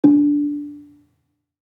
Gambang-D3-f.wav